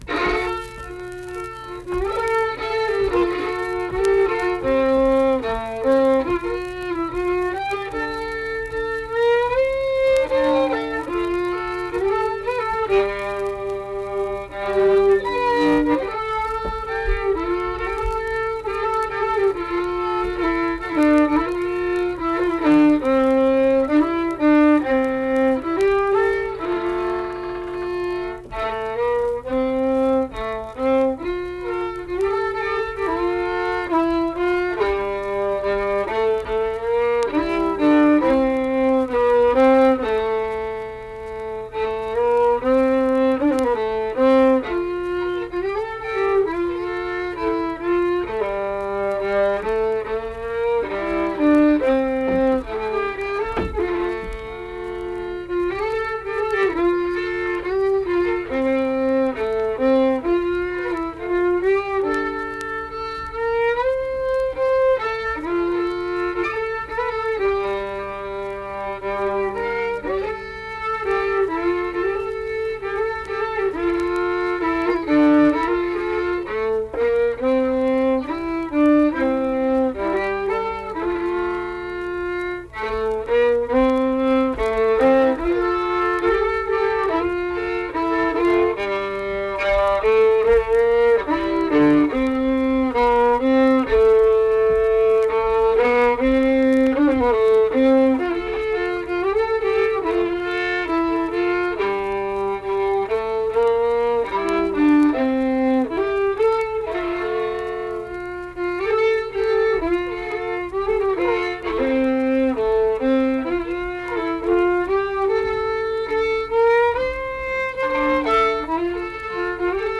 Accompanied guitar and unaccompanied fiddle music performance
Hymns and Spiritual Music, Instrumental Music
Fiddle